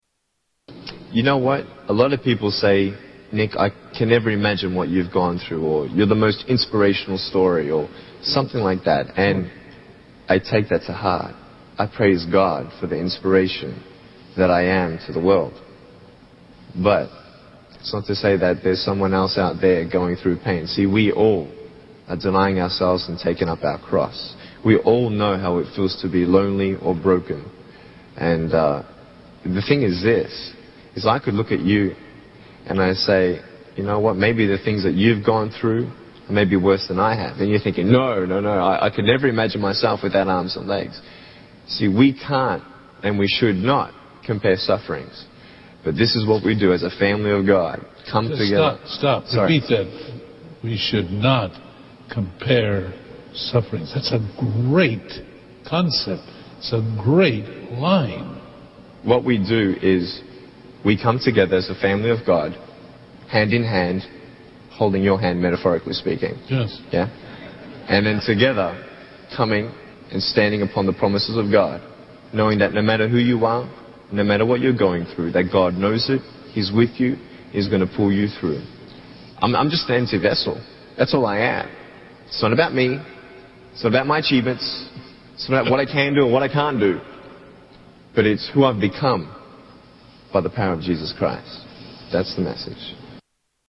Nick Vujicic's testimony